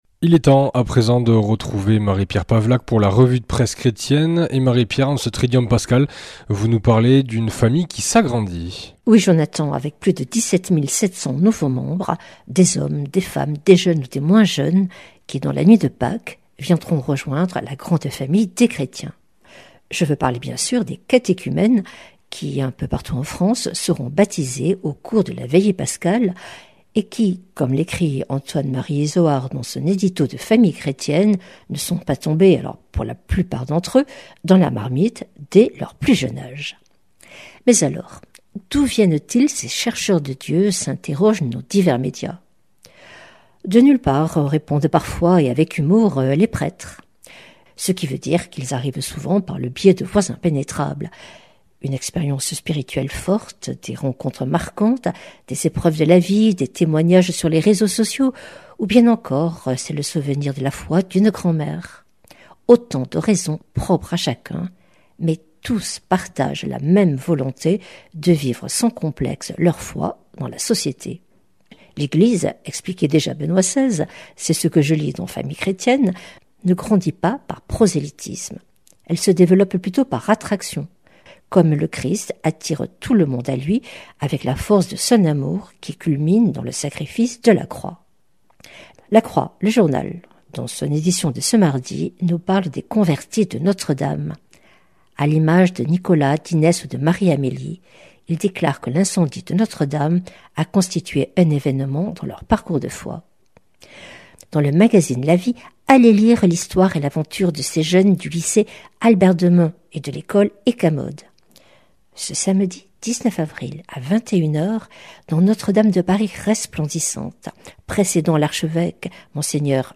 vendredi 18 avril 2025 La revue de presse chrétienne Durée 5 min
Revue de presse